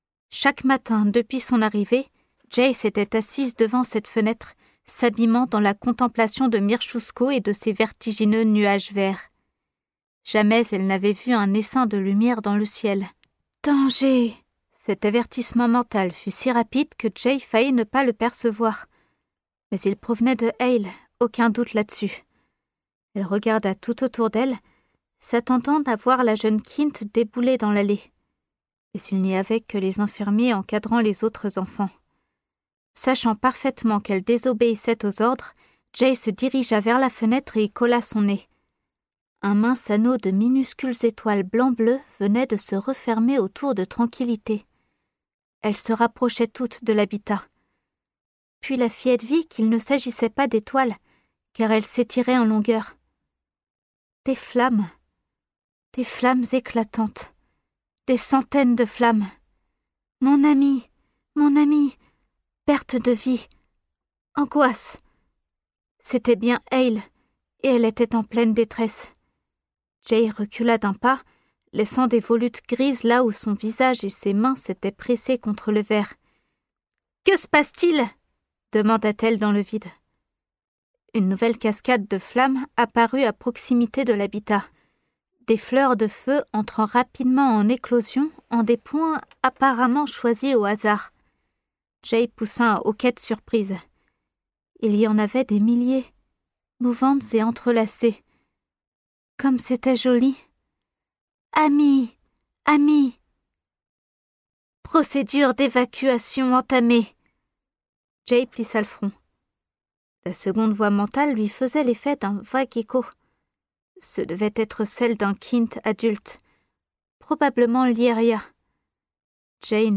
Narration - Sci-fi YA
5 - 30 ans - Soprano